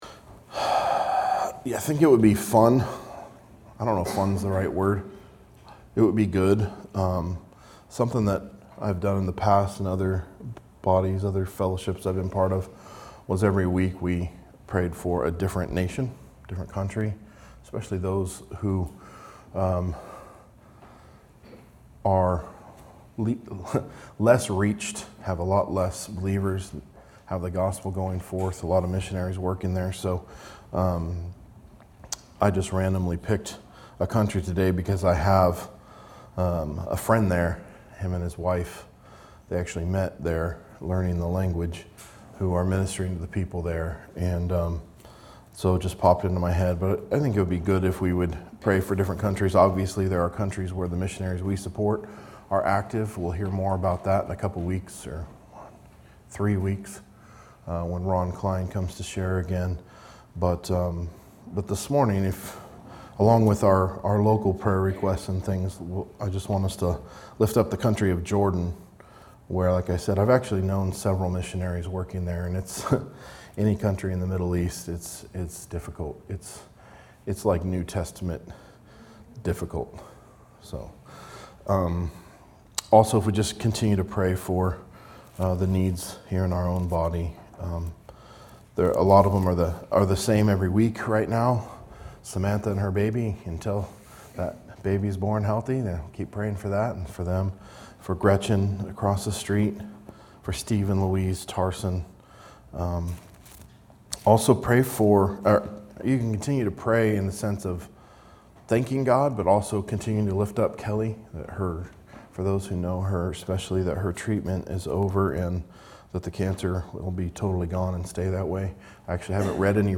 A message from the series "Matthew." Matthew 25:31-46